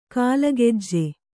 ♪ kālagejje